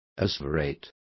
Complete with pronunciation of the translation of asseverated.